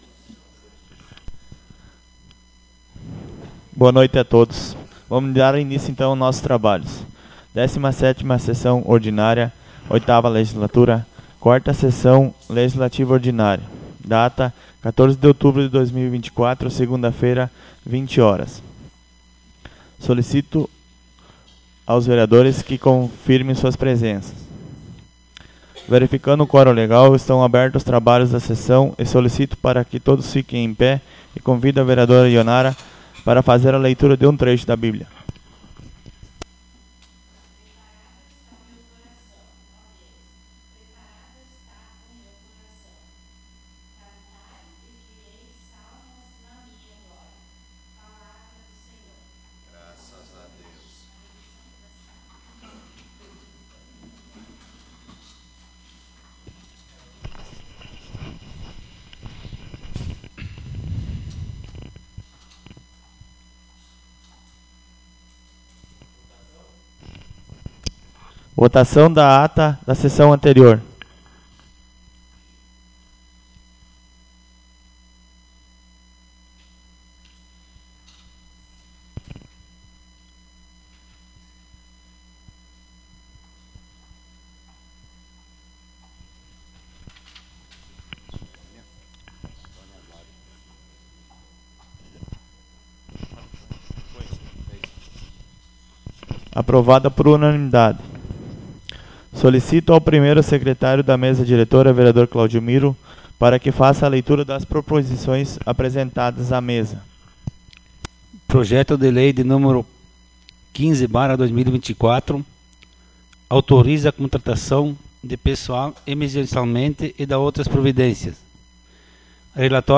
Audio da 17ª Sessão Ordinária 14.10.24